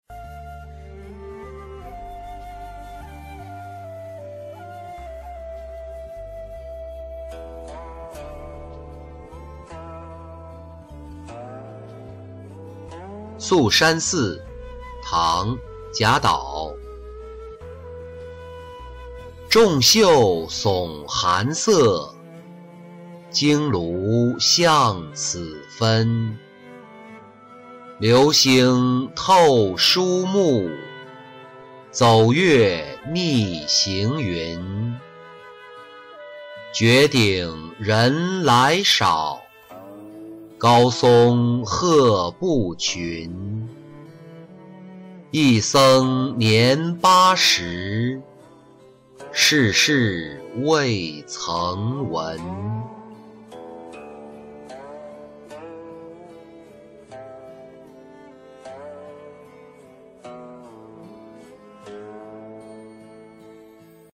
宿山寺-音频朗读